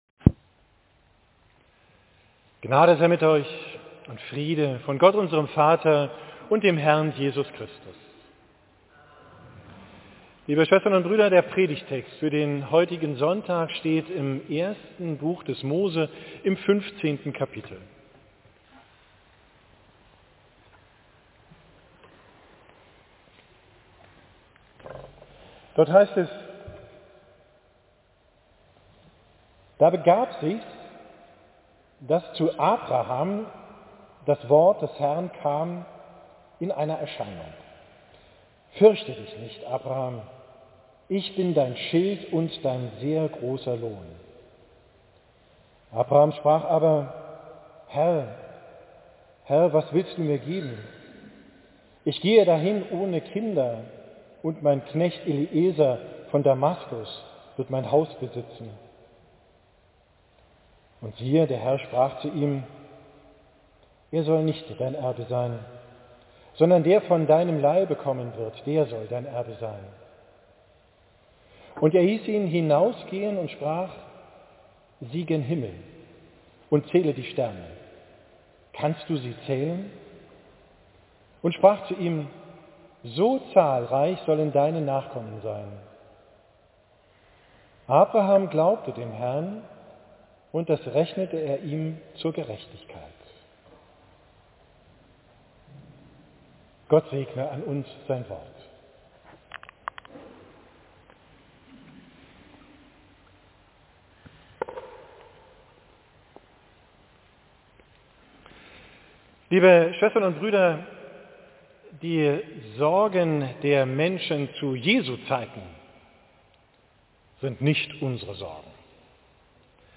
Predigt vom 15.